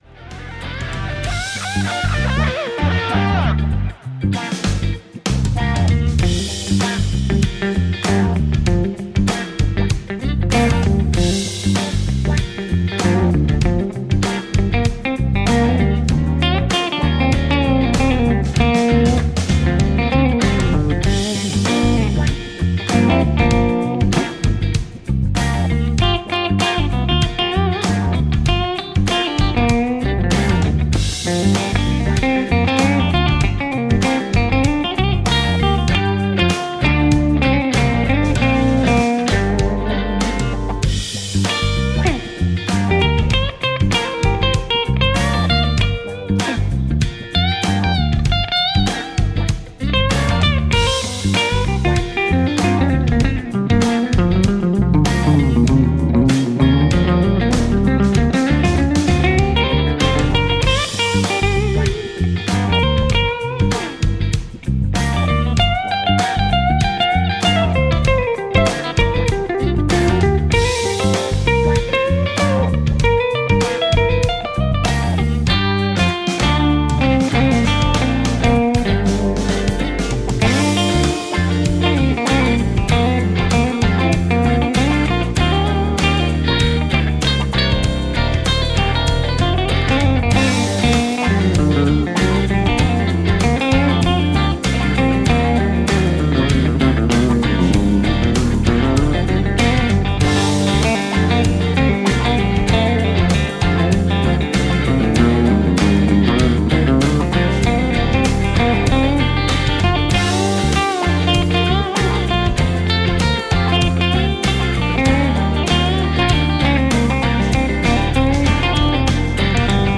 Wen es interessiert und wer damit etwas anfangen kann: Ich habe 2 Instrumente verwendet.: - Einmal ein Hohner Clavinet D6 von meinem Nordstage 2 EX 88 Piano, mit Flanger-Effekt drauf...
Hammond-Clone
So habe ich den ersten Piano-Take genommen und dann einen 2. Take ab Einsatz der Orgel gemacht. Die Spuren dann lautstärkemäßig nachbearbeitet, damit sich nichts zu laut überlagert, da ich während der Orgelpassagen in der linken Hand das Clavinet als Rhythmusunterlage weitergespielt habe.